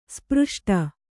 ♪ spřṣṭa